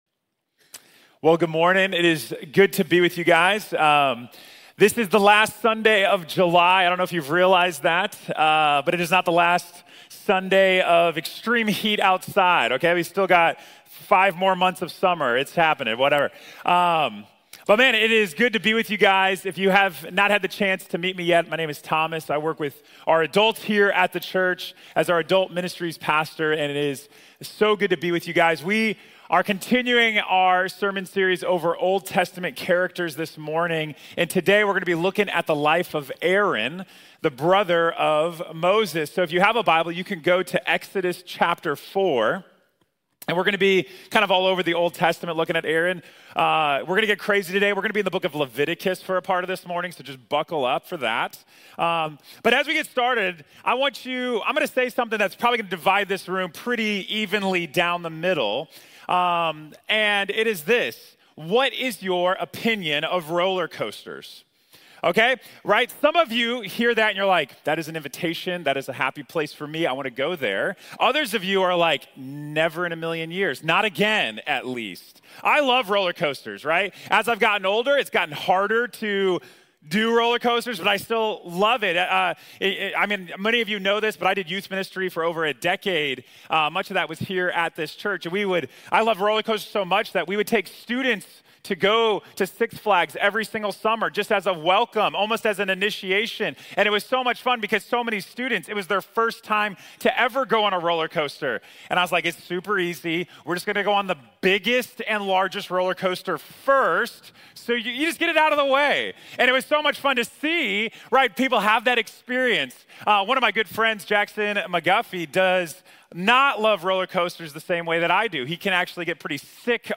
Aaron | Sermon | Grace Bible Church